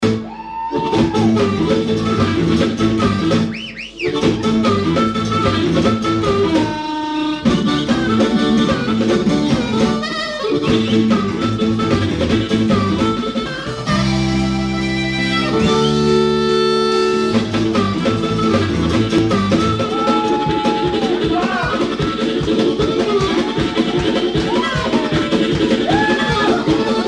D'influences country, rock et celtique
Simple, efficace et très vivant.